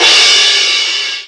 CRASH11   -R.wav